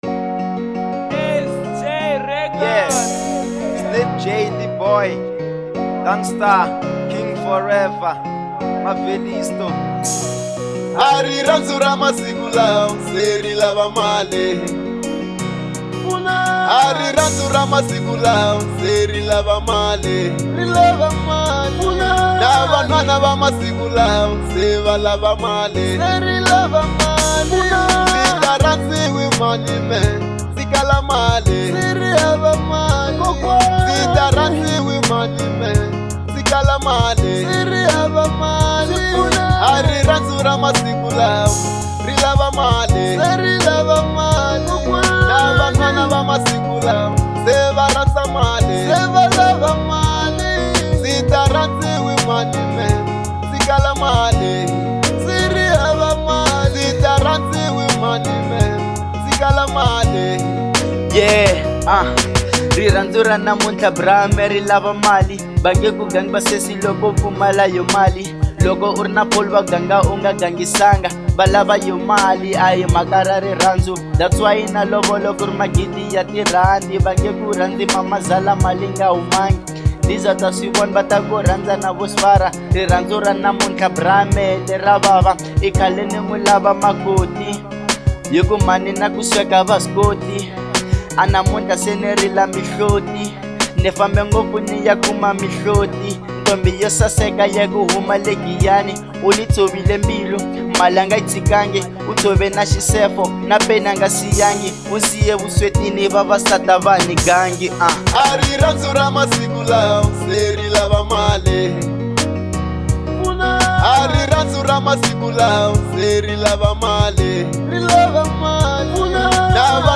05:00 Genre : Hip Hop Size